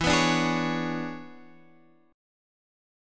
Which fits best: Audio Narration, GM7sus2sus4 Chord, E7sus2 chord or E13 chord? E13 chord